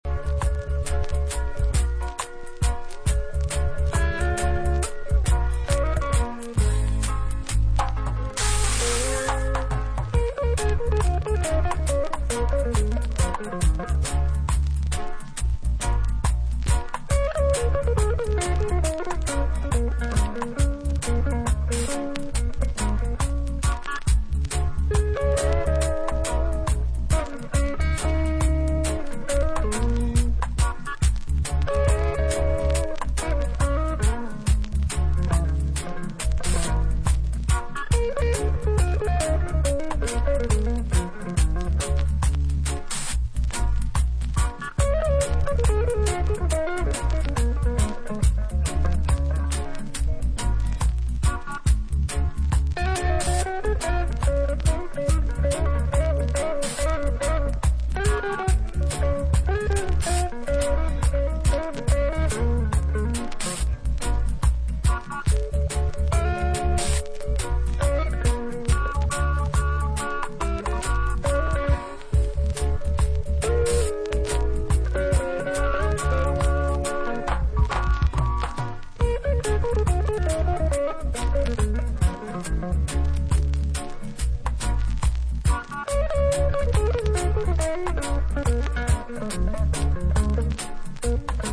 Reggae Dub Roots Reggae
文句なしで最高なインスト・レゲエ名盤